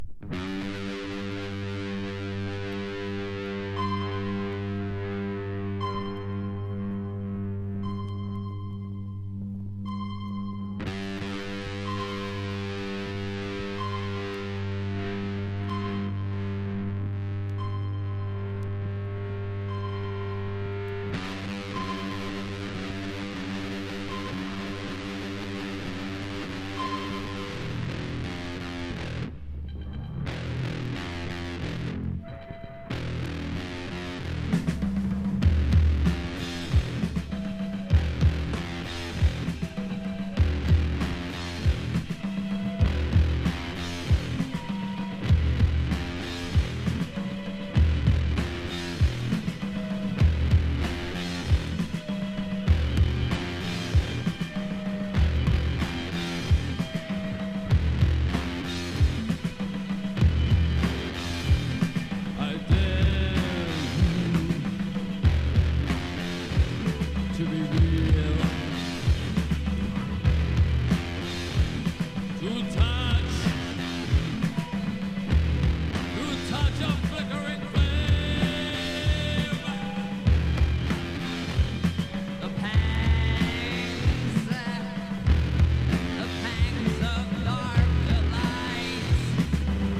POST PUNK